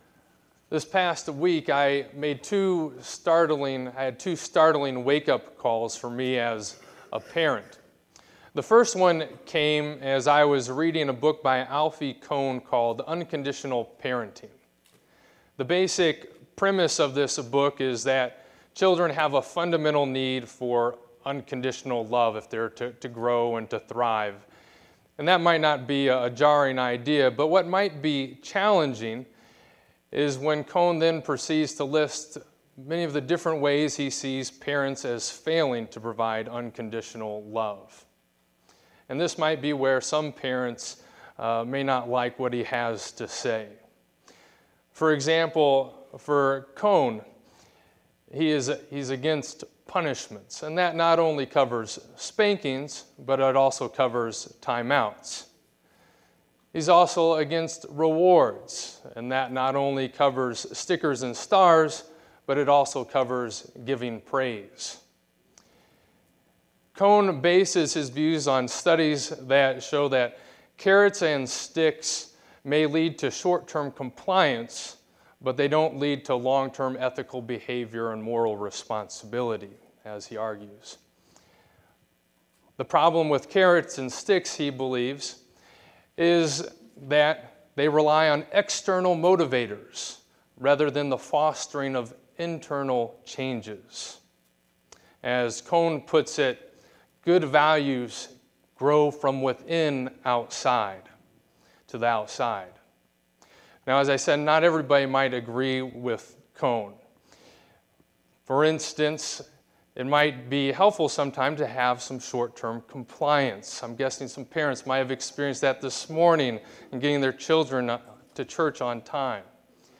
Second Scripture Reading—John 15: 7-12